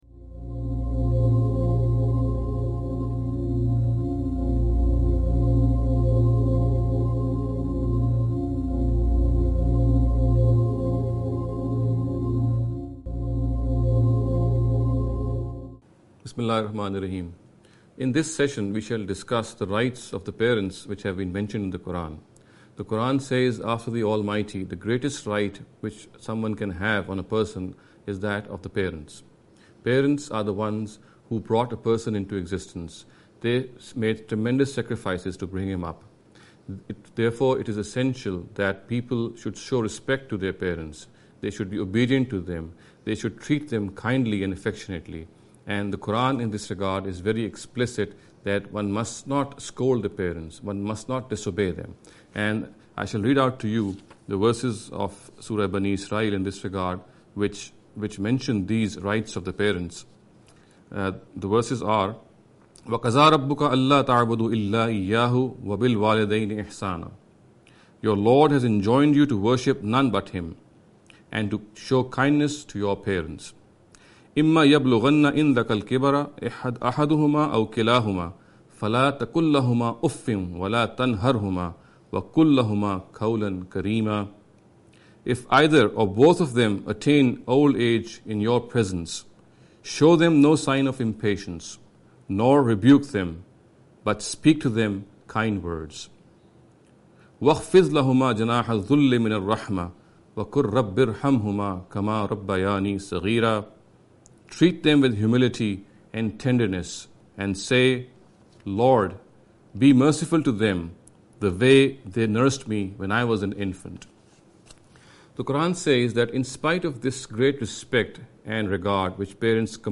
This lecture series will deal with some misconception regarding the Understanding The Qur’an. In every lecture he will be dealing with a question in a short and very concise manner. This sitting is an attempt to deal with the question 'Rights of The Parents’.